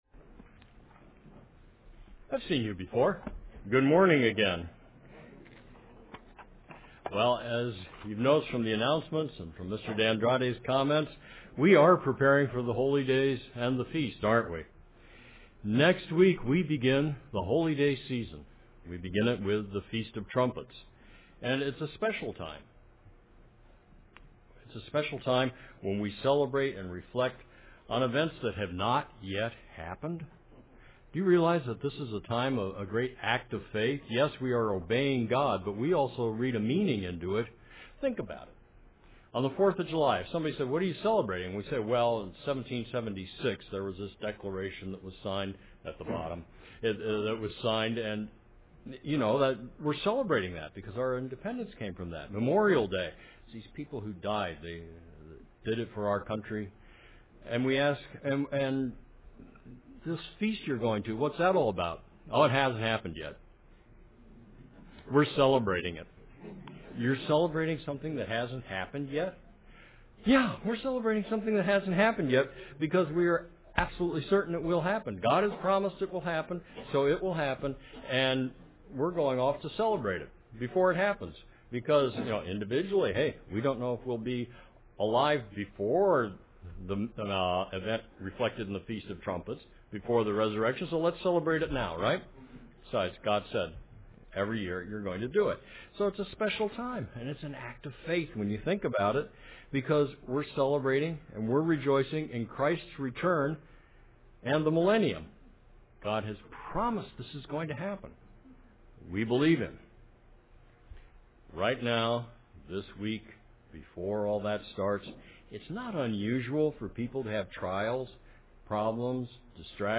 Scriptures read in this sermon: